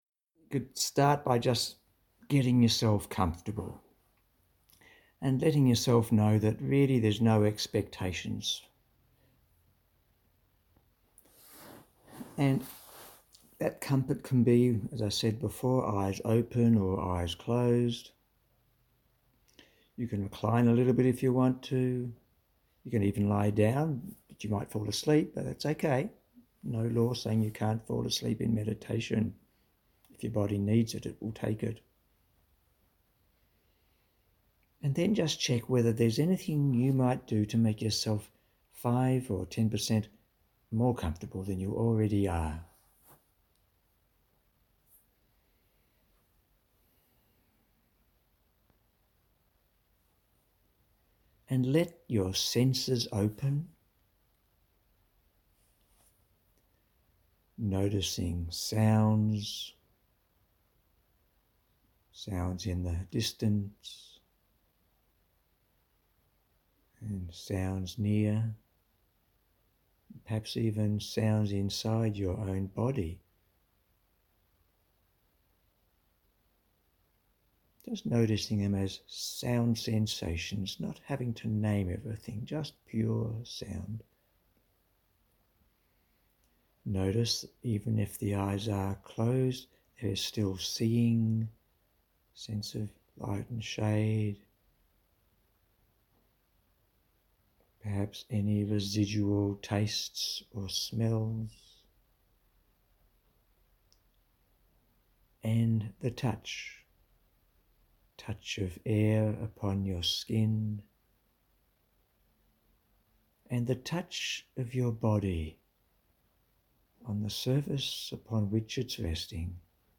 A 35 minute meditation helping to deepen the connection to your Higher Power and exploring sensations throughout the body and mind.